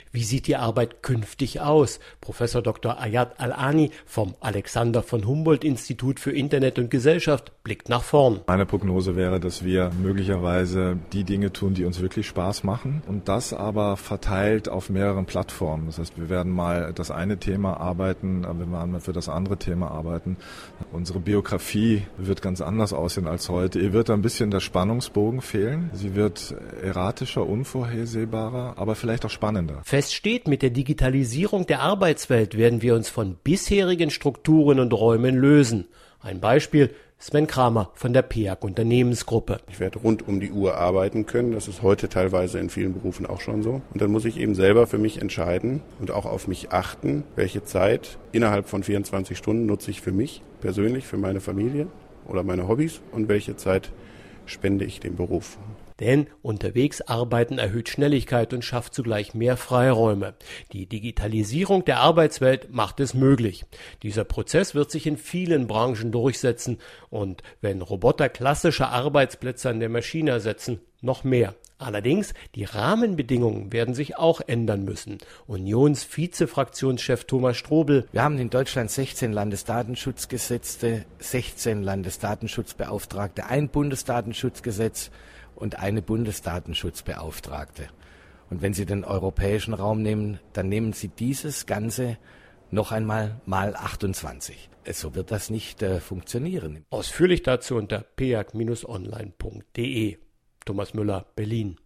Radiobeitrag